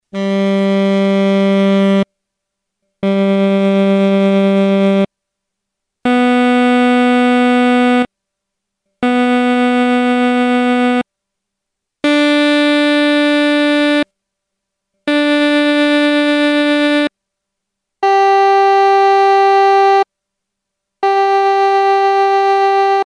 It too produced the buzzing/tremolo, and an occasional large vibrato.
Good/Bad Notes Tremolo